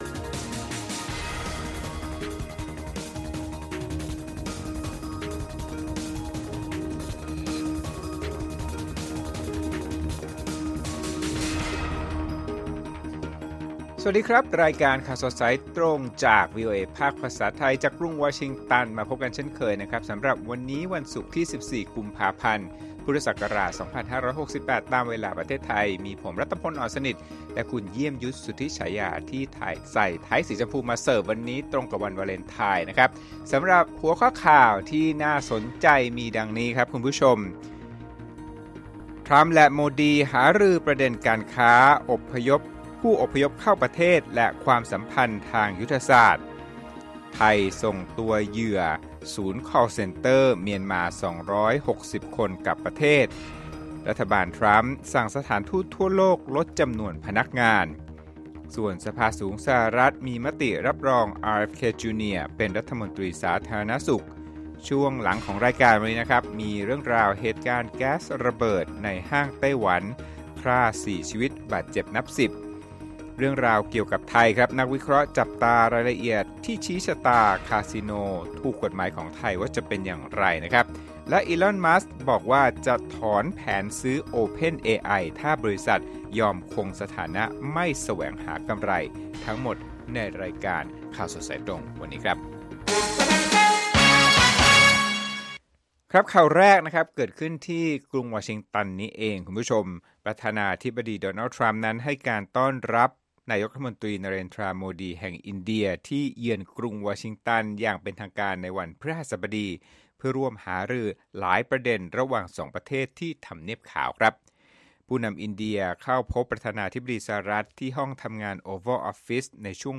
ข่าวสดสายตรงจากวีโอเอไทย วันศุกร์ ที่ 14 ก.พ. 2568